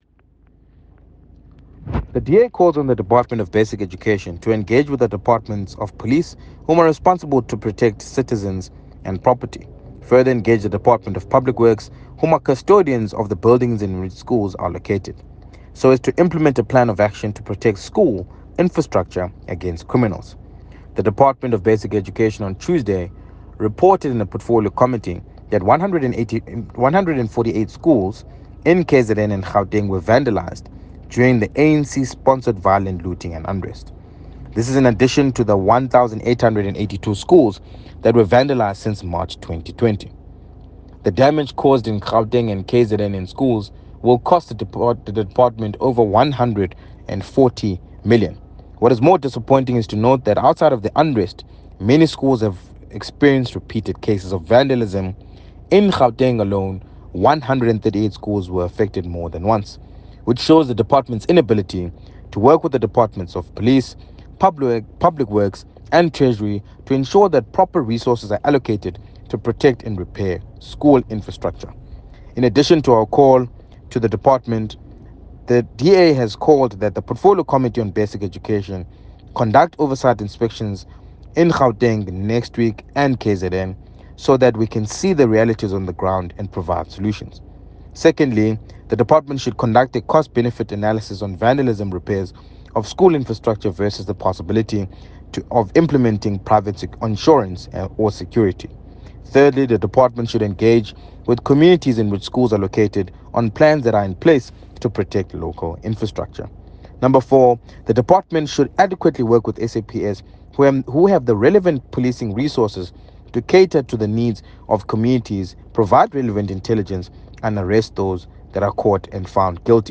soundbite by Baxolile ‘Bax’ Nodada MP.